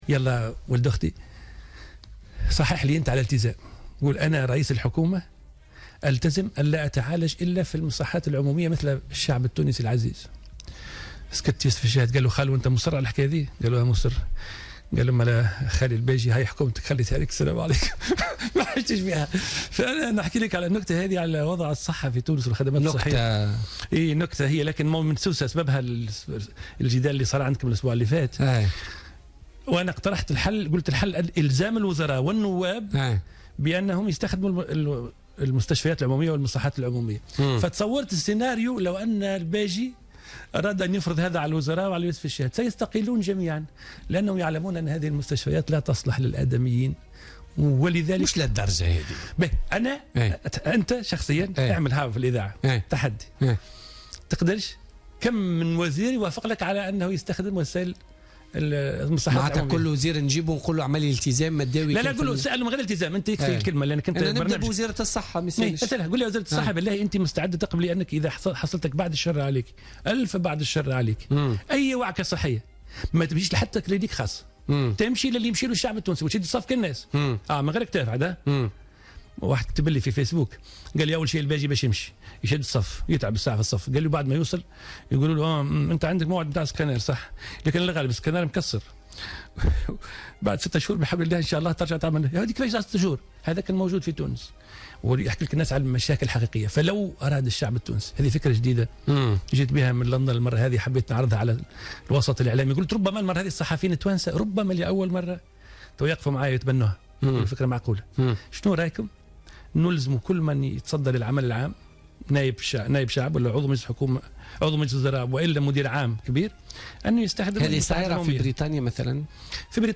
أكد رئيس تيار المحبة الهاشمي الحامدي ضيف بوليتيكا اليوم الاثنين أنه فكر في تقديم مقترح لأعضاء الحكومة وأعضاء مجلس الشعب وهي الالتزام بالعلاج والمداواة في المستشفيات العمومية للحث على تحسين الخدمات الصحية.